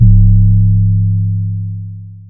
YM Sub 9.wav